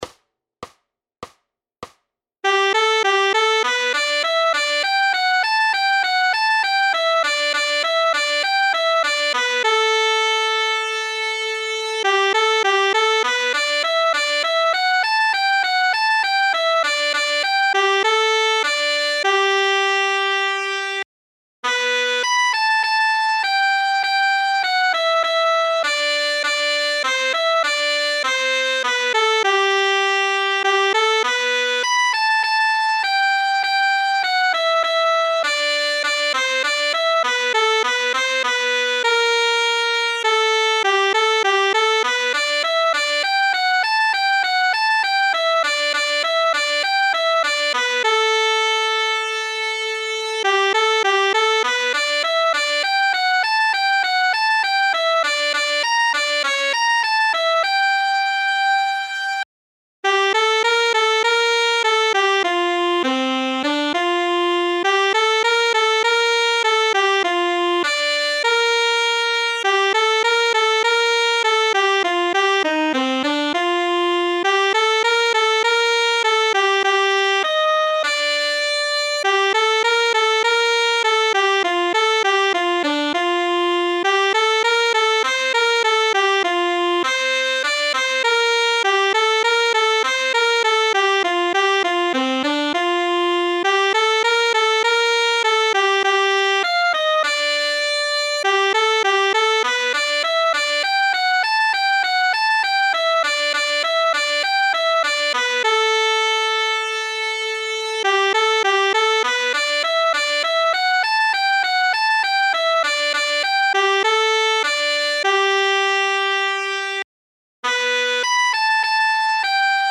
Noty na tenorsaxofon.
Hudební žánr Klasický